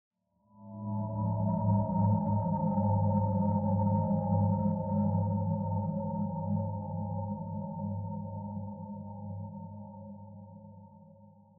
sonarAmbience3.ogg